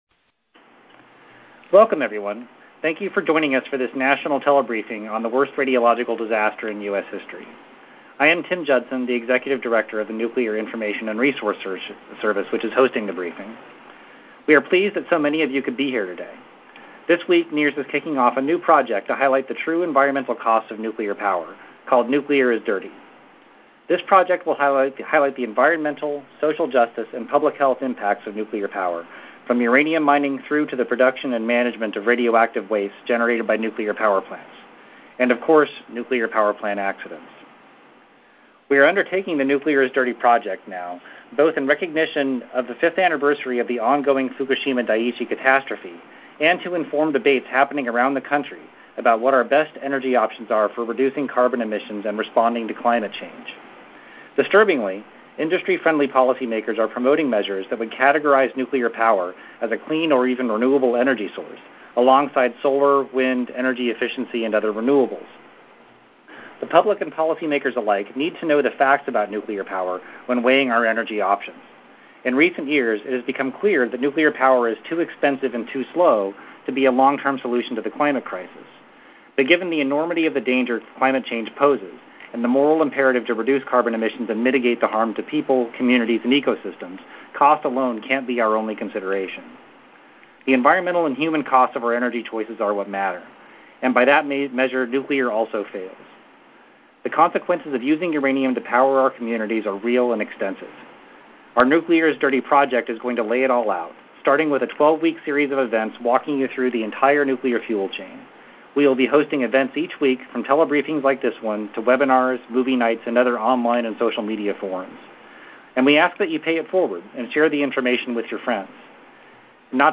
Audio file (mp3) of NIRS telebriefing on 1979 uranium mining disaster at Church Rock, New Mexico.